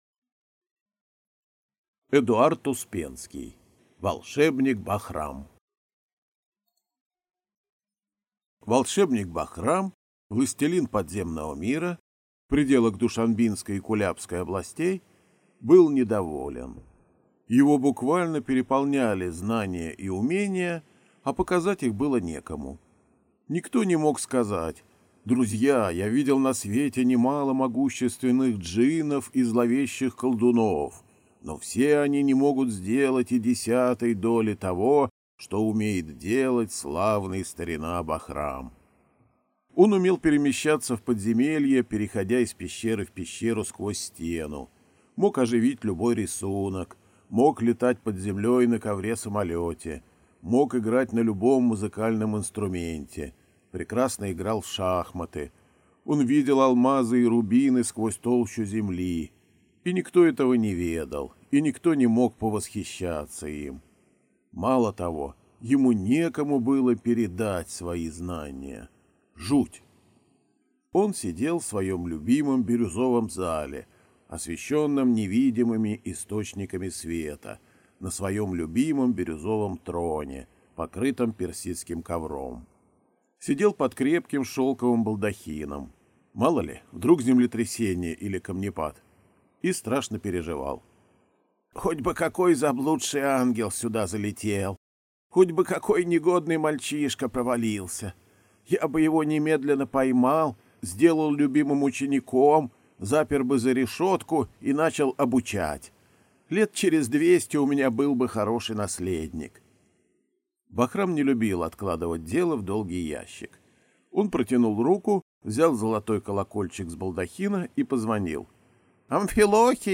Аудиокнига Волшебник Бахрам | Библиотека аудиокниг